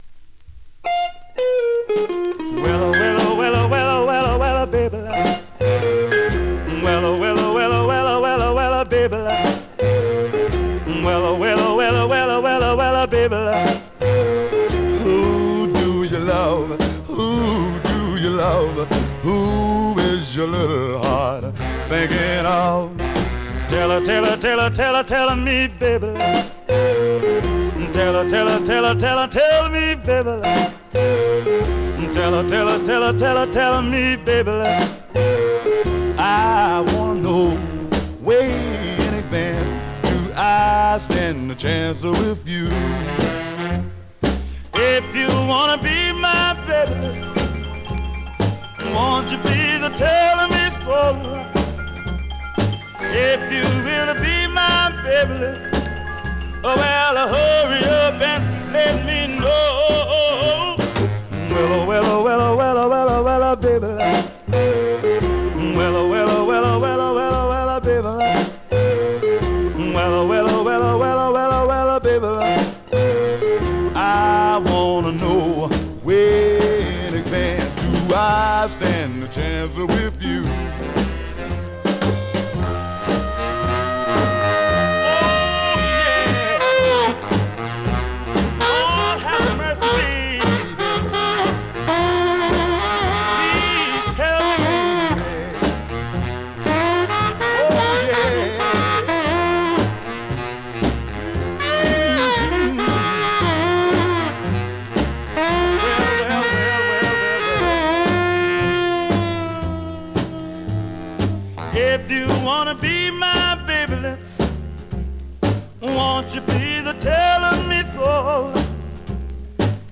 Vinyl has a few light marks plays great .
Great mid-tempo Rnb / Mod dancer from this popular artist
R&B, MOD, POPCORN